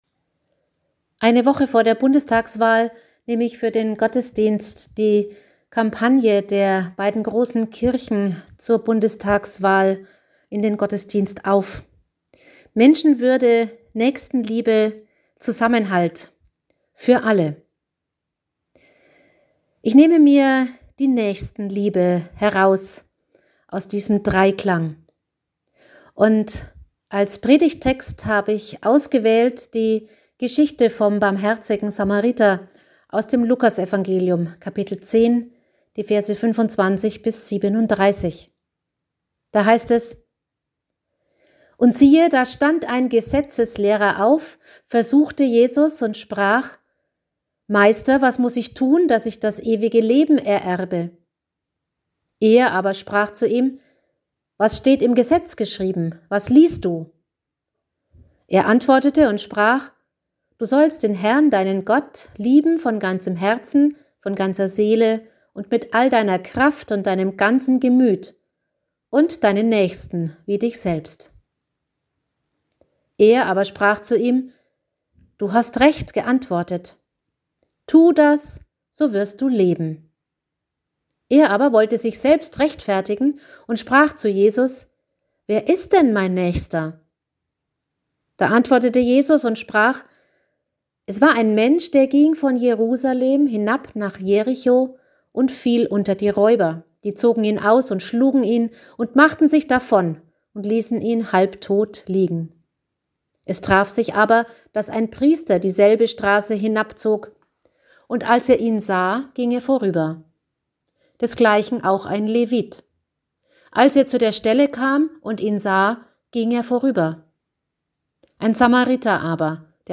Themapredigt.Naechstenliebe.mp3